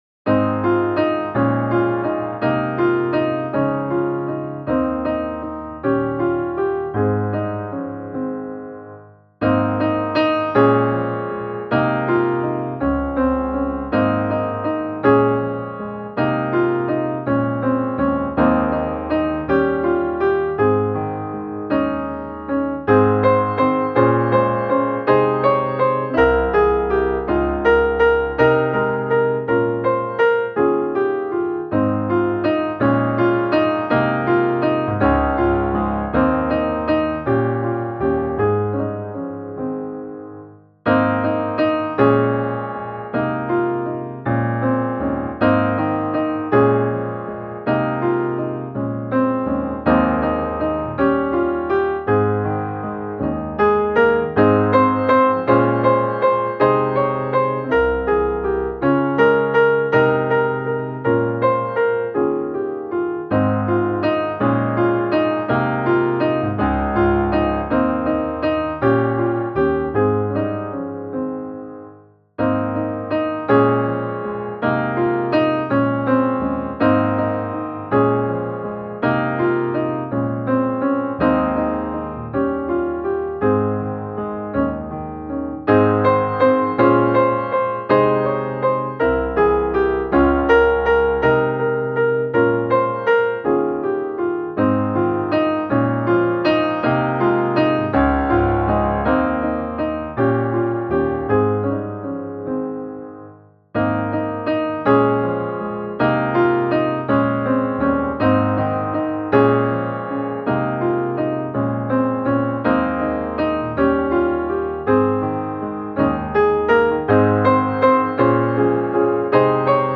Pris vare Gud, vare Fadern - musikbakgrund